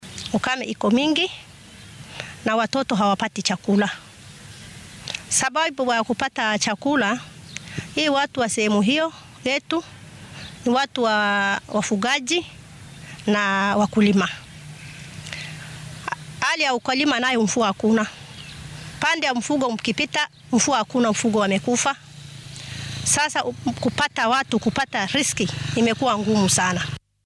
Haweenay ka mid ah dadka ku nool ismaamulka Tana River ayaa ka hadleysa xaaladda abaarta iyo saameynta ay ku yeelatay.